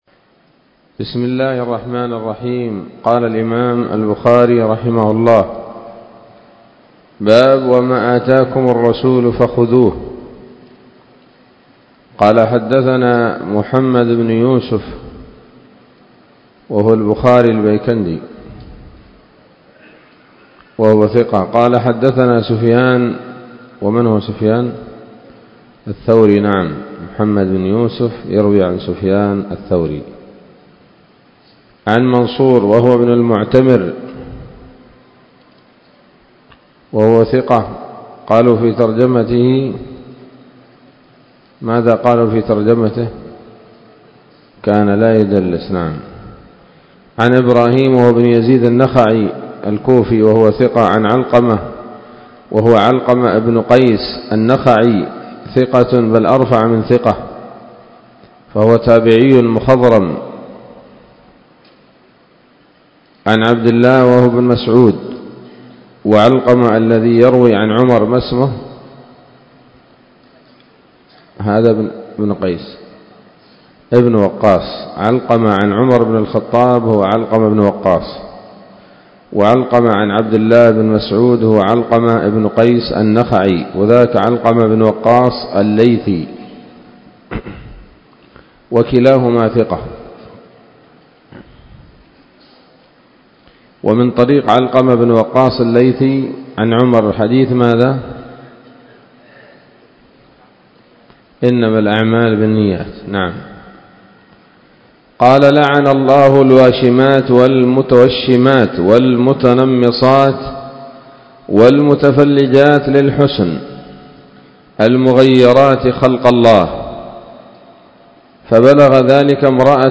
الدرس الرابع والخمسون بعد المائتين من كتاب التفسير من صحيح الإمام البخاري